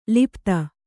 ♪ lipta